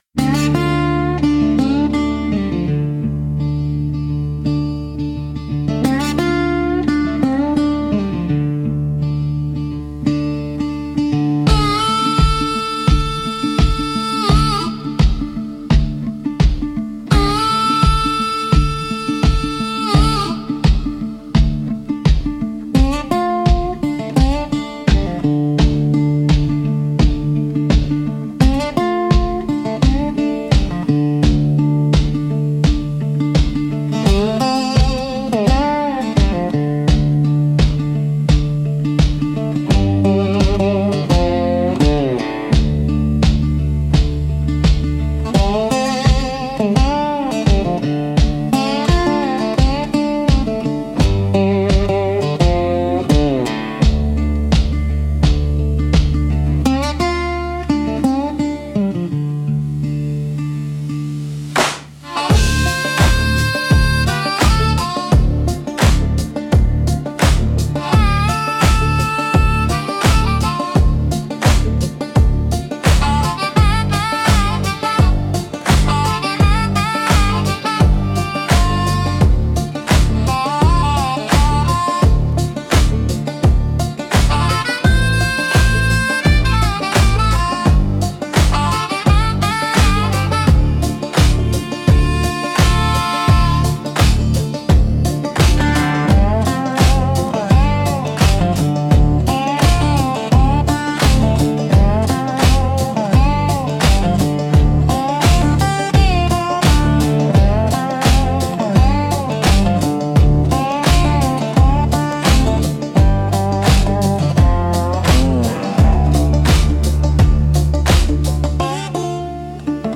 Instrumental - Tin Roof Testament 2.32